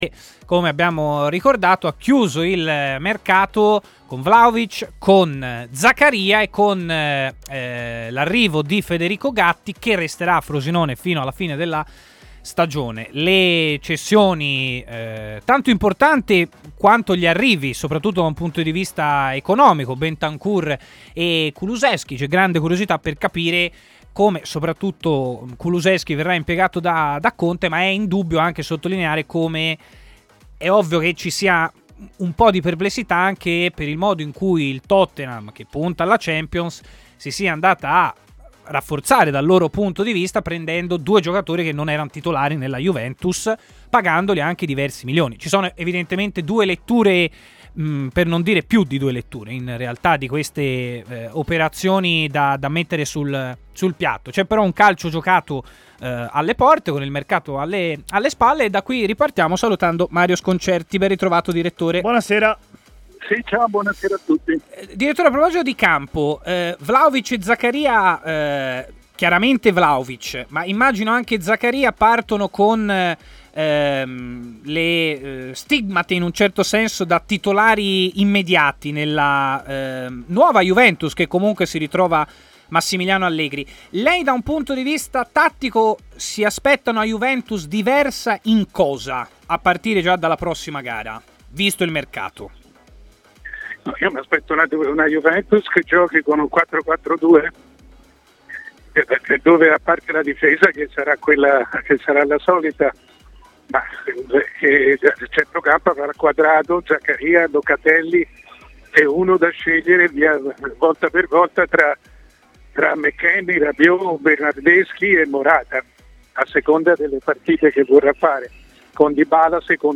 L'opinionista Mario Sconcerti, decano del giornalismo sportivo, è intervenuto in diretta durante Stadio Aperto, trasmissione di TMW Radio